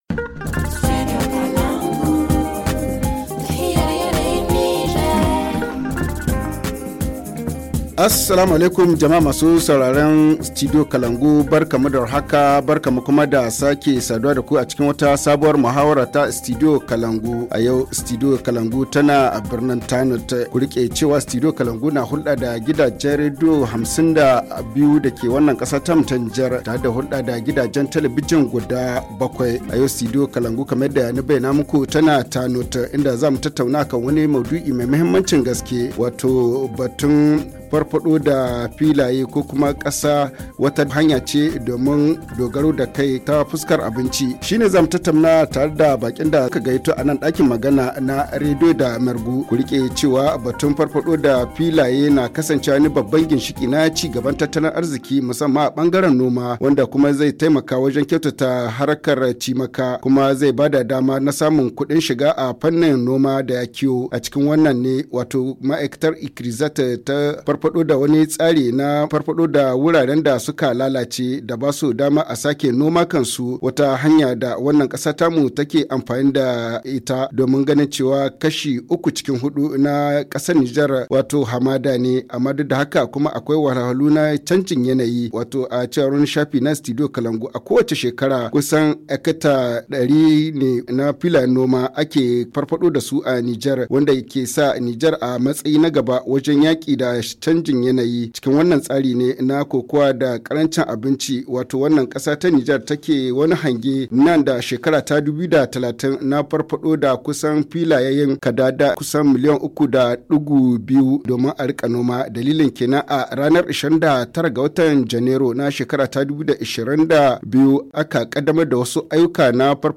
HA Le forum en haoussa Télécharger le forum ici.